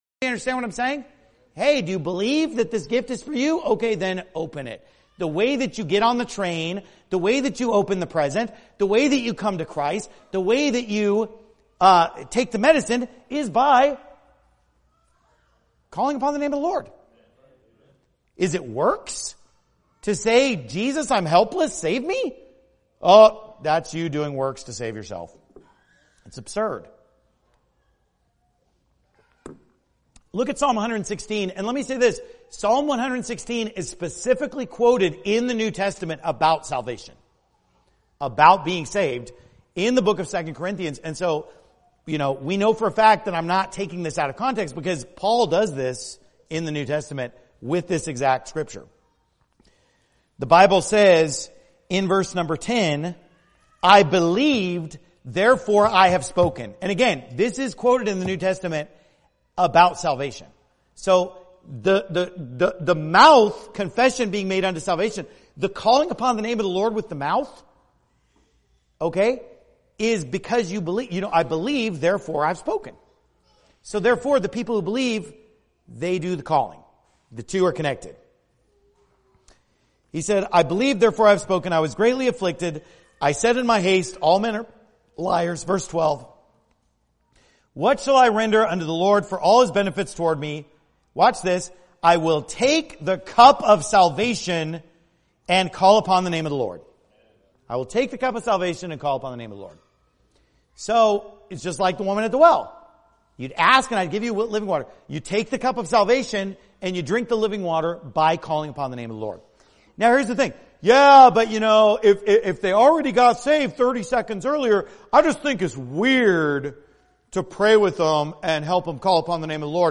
Here is a 7-minute excerpt from the sermon.